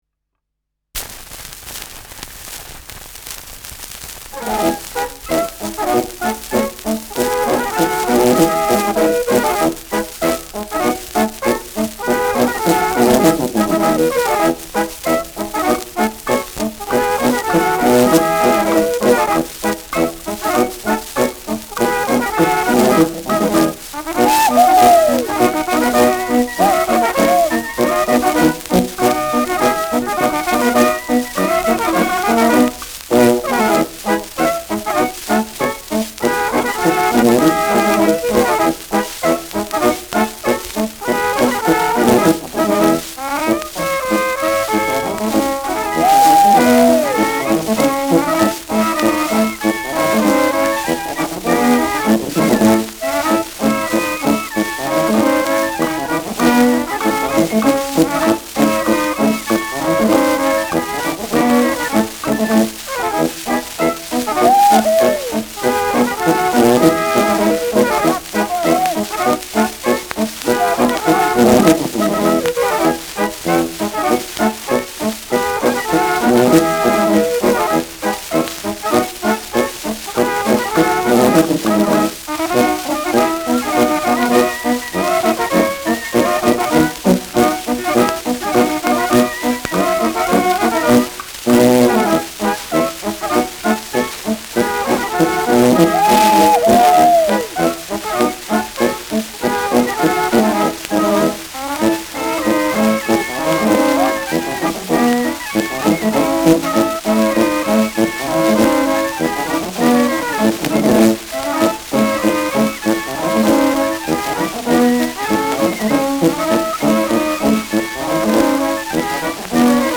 Schellackplatte
leichtes Rauschen : Knistern
Mit Juchzern, Zwischenrufen und rhythmischen Schlägen.